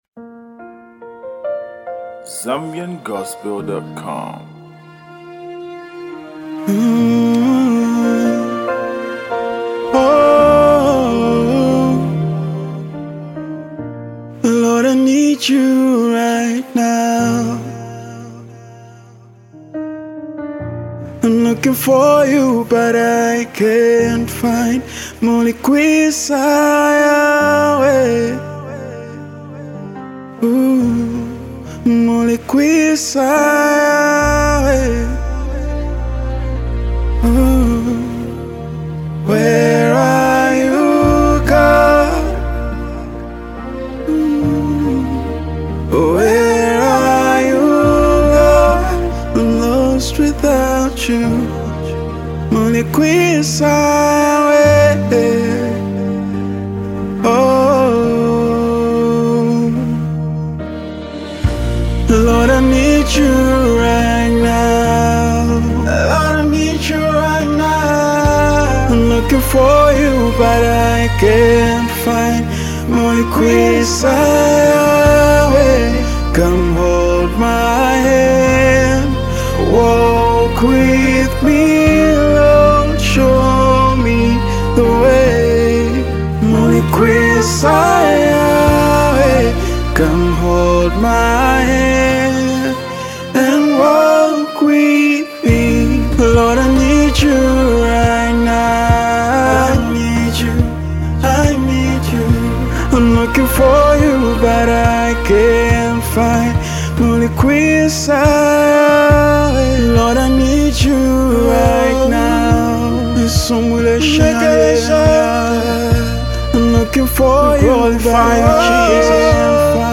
deeply touching song